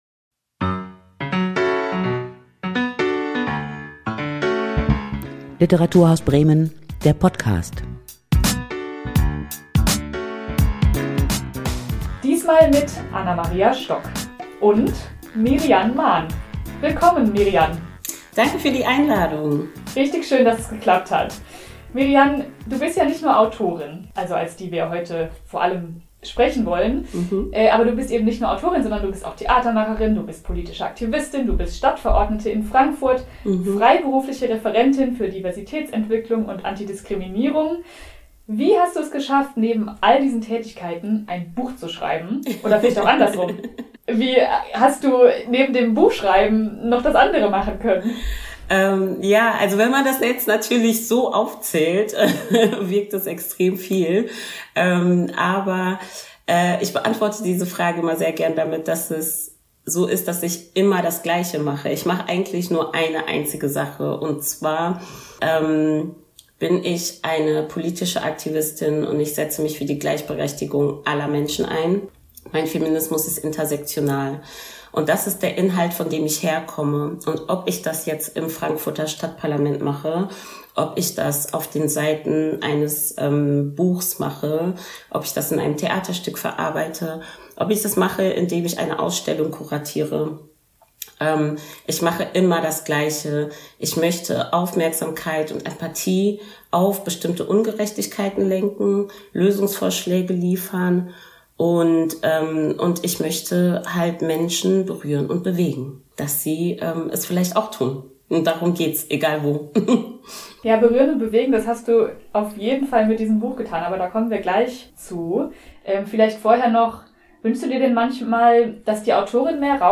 „Issa“ – so heißt der Debütroman von Mirrianne Mahn. Im Gespräch